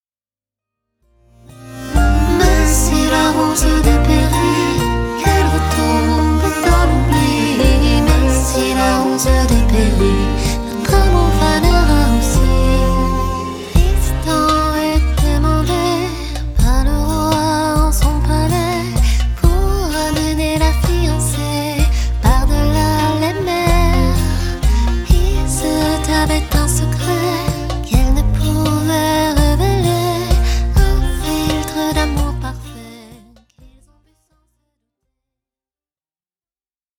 harpiste chanteuse